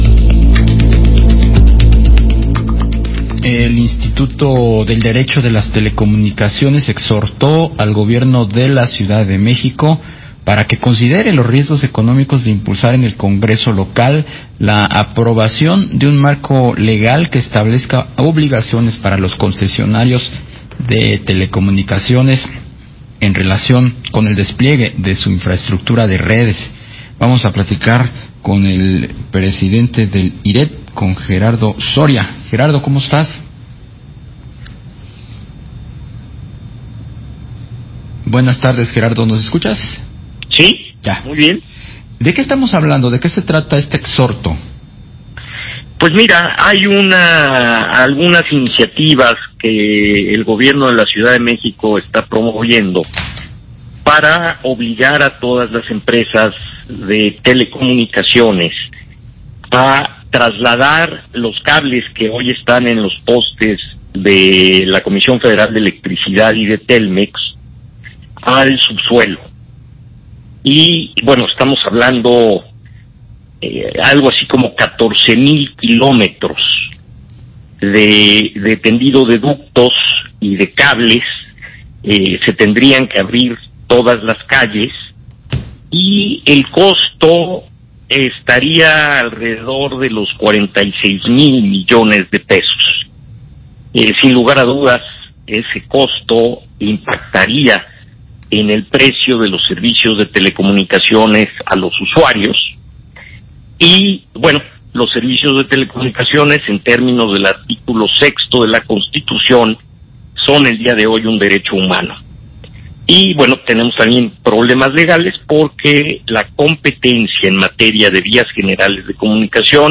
vía Imagen Radio.
Entrevista